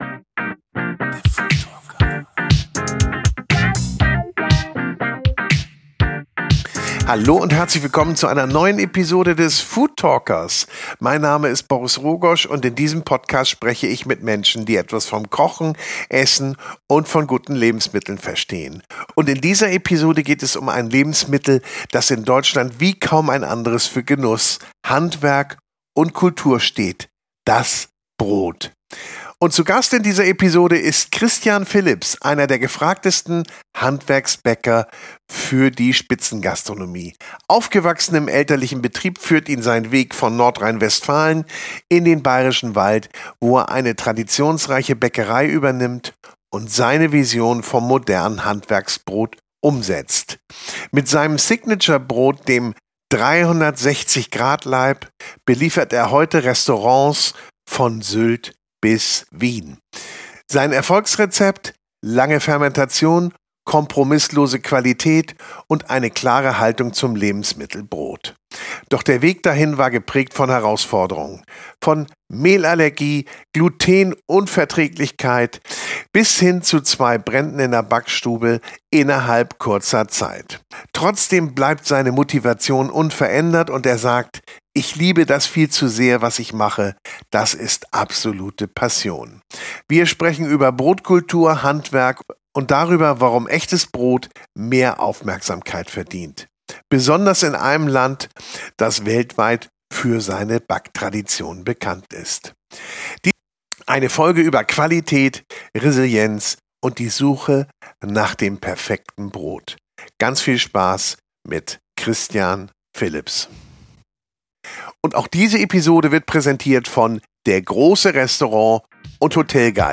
Im Gespräch geht es um Handwerk, Fermentation und die Bedeutung von Zeit als wichtigsten Rohstoff.